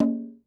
Conga2Lo.wav